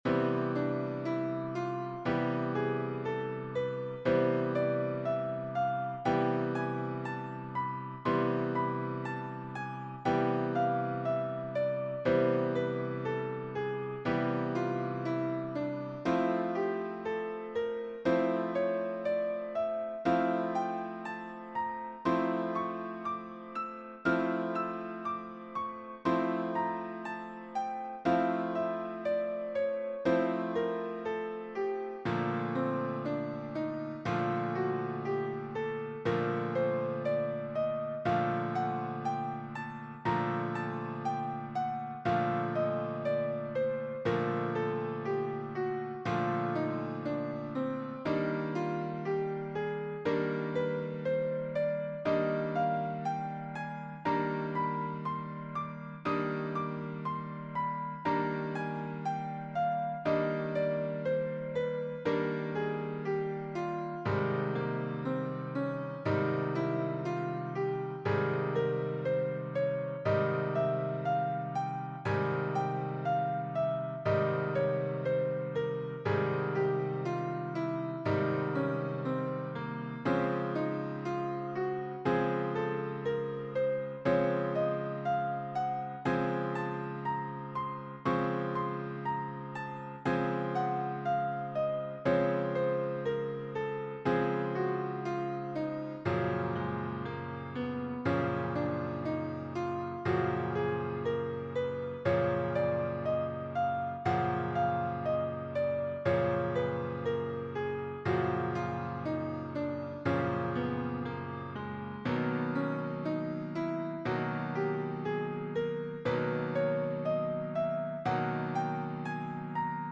Improvisation Piano Jazz
Gamme Be Bop Majeure pour tous les tons
gamme_be_bop_cycle.mp3